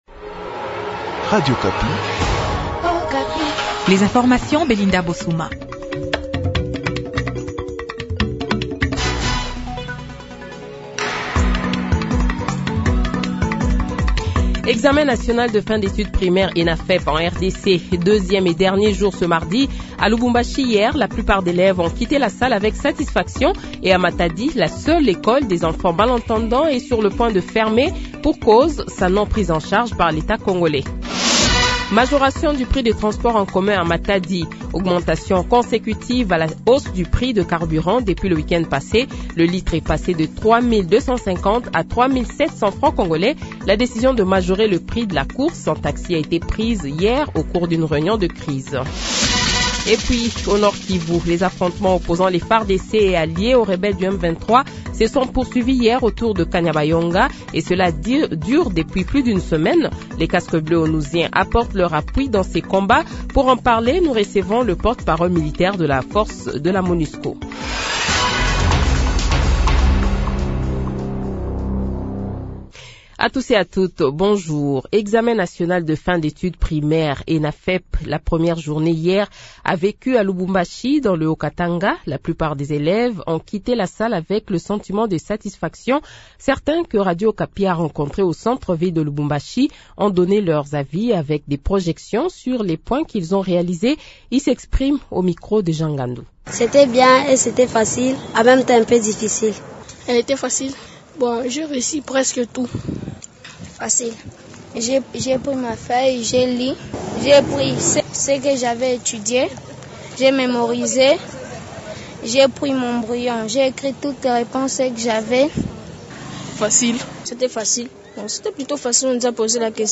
Le Journal de 8h, 04 Juin 2024 :